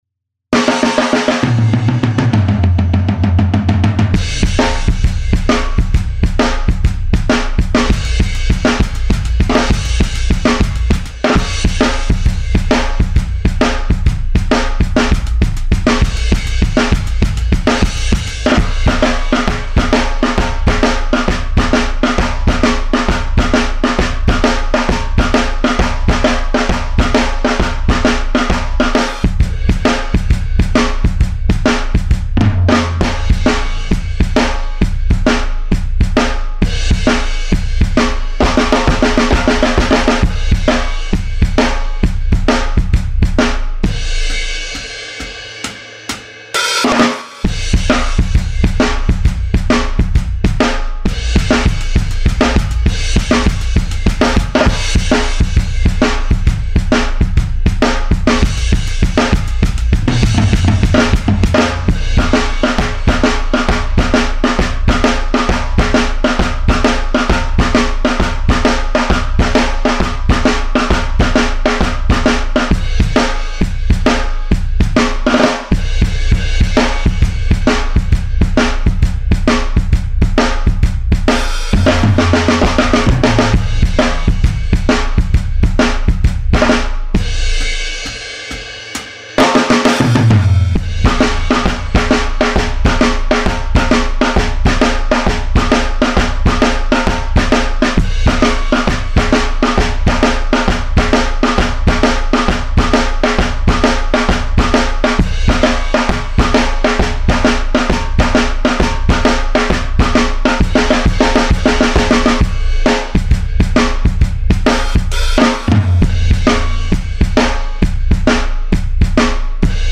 Studio Kit